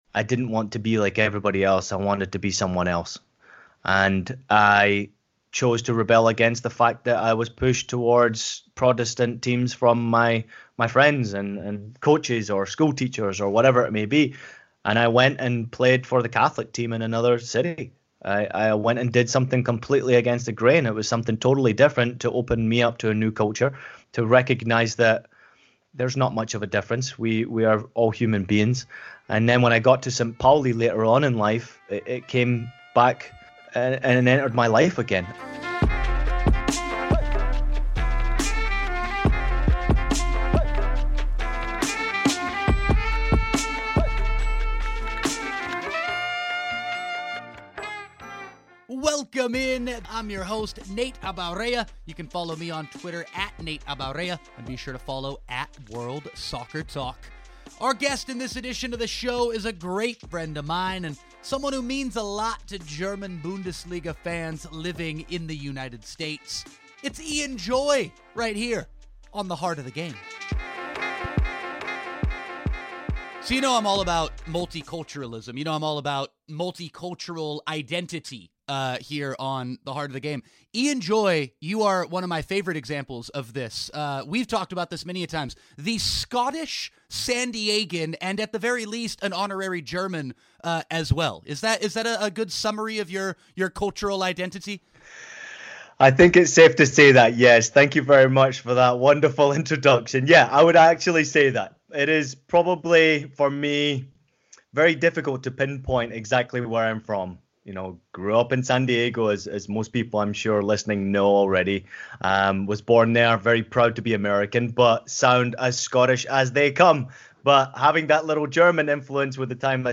The Heart of The Game is a brand-new weekly podcast featuring interviews with the studio talent, commentators, players and coaches that bring us the beautiful game.
interview